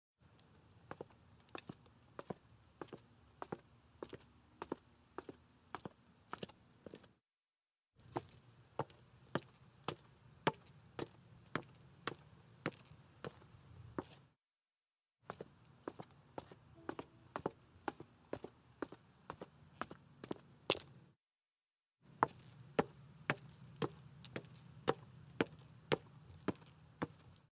دانلود صدای کفش هنگام راه رفتن 4 از ساعد نیوز با لینک مستقیم و کیفیت بالا
جلوه های صوتی